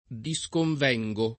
vai all'elenco alfabetico delle voci ingrandisci il carattere 100% rimpicciolisci il carattere stampa invia tramite posta elettronica codividi su Facebook disconvenire v.; disconvengo [ di S konv $jg o ], ‑vieni — coniug. come venire